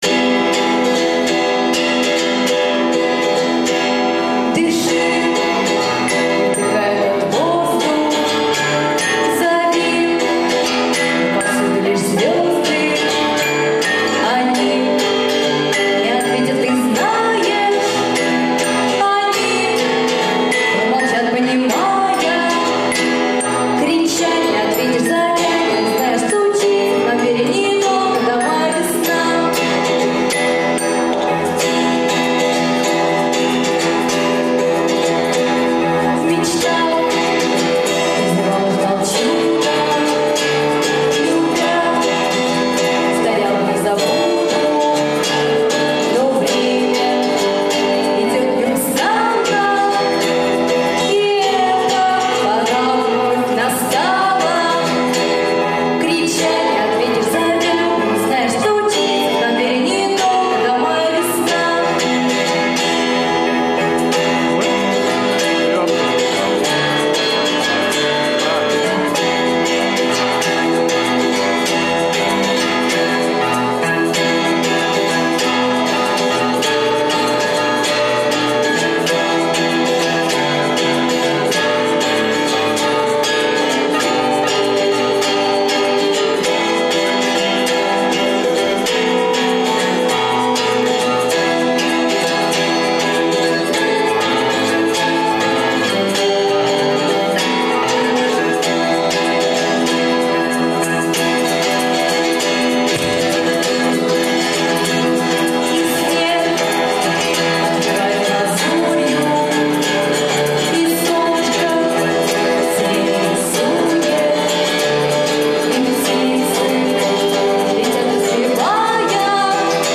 Песни с концертов: